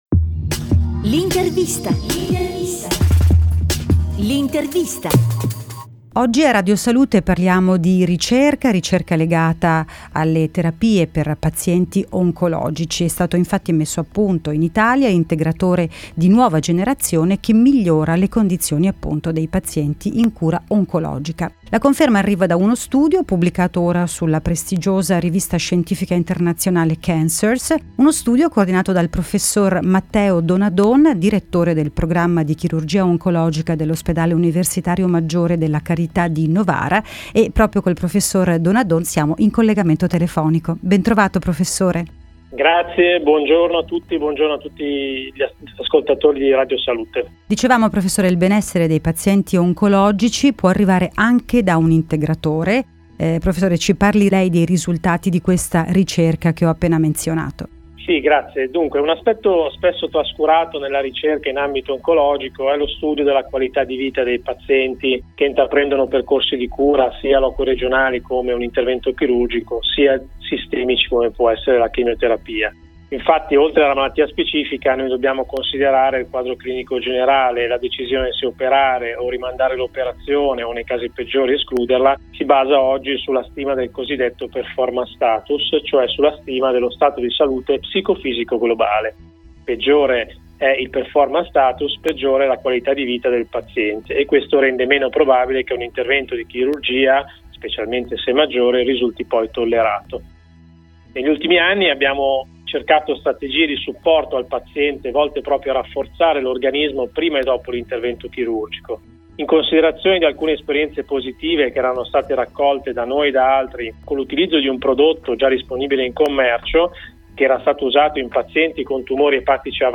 • Radio
radiosalute_tumore_al_fegato.mp3